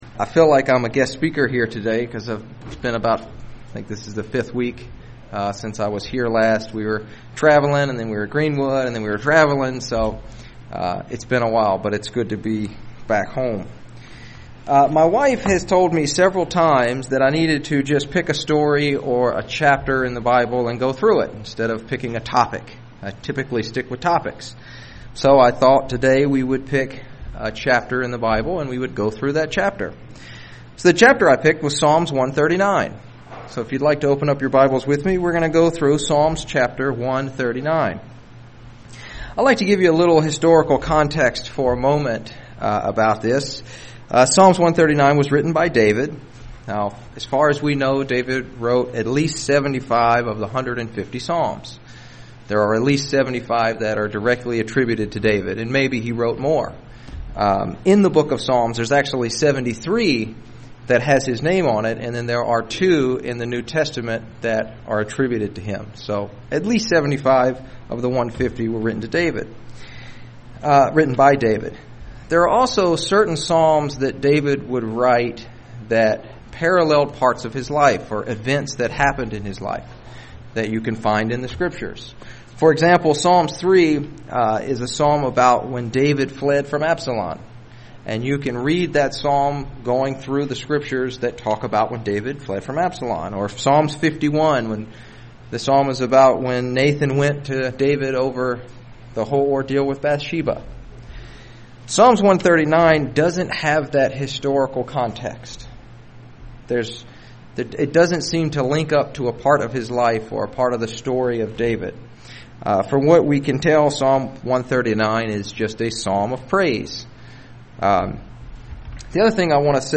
Given in Lehigh Valley, PA
UCG Sermon